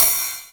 VEC3 Cymbals Ride 27.wav